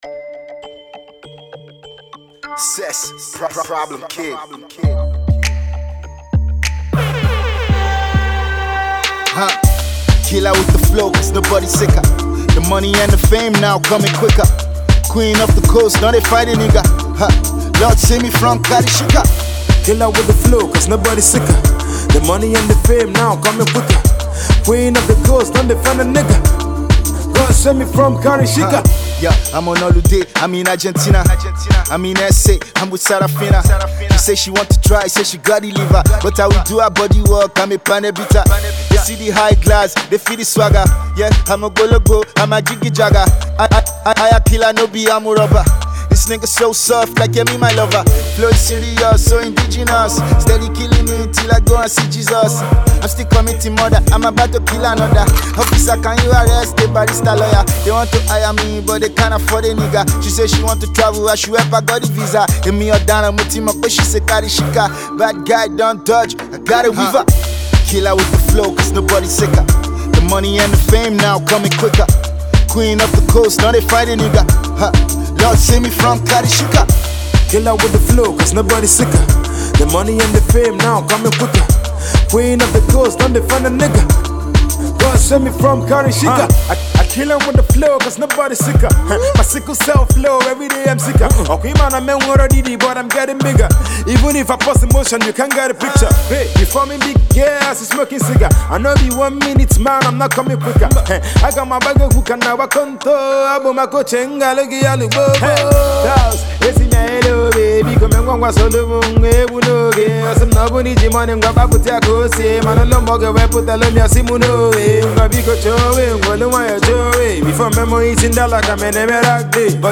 Rap song
Insanely hilarious outro